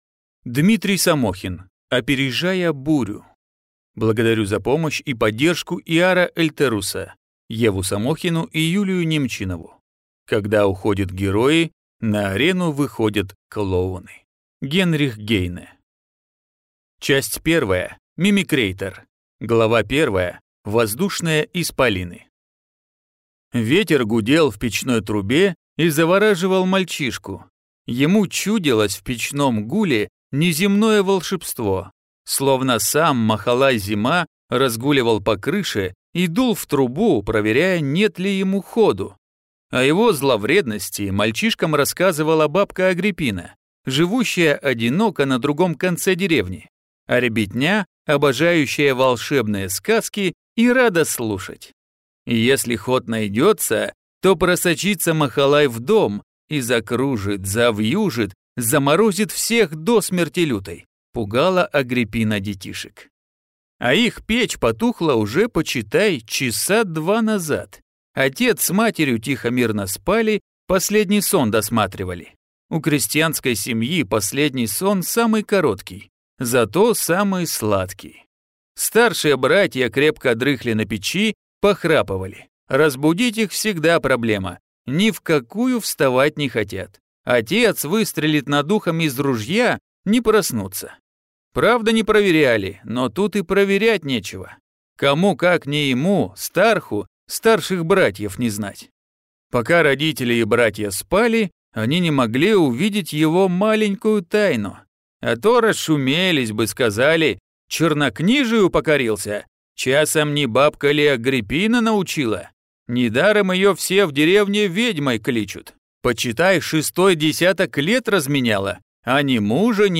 Прослушать и бесплатно скачать фрагмент аудиокниги